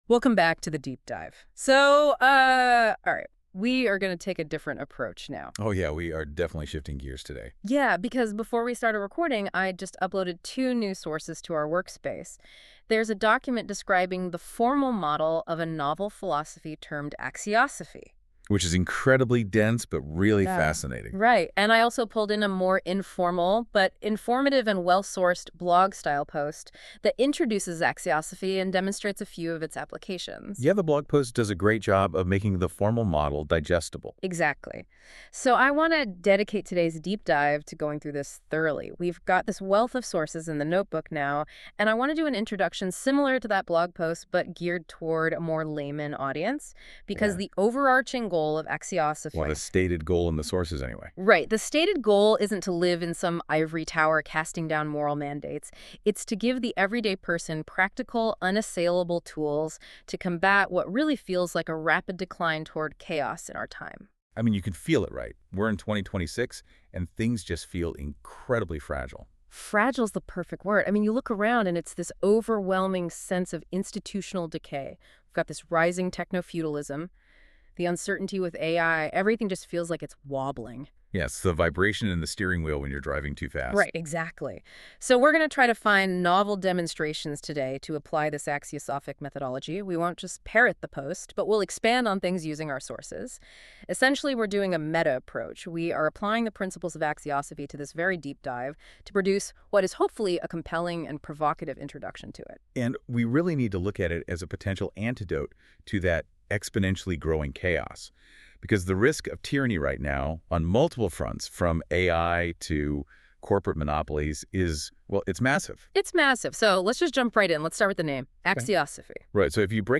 deep dive a dialectic introduction you can listen to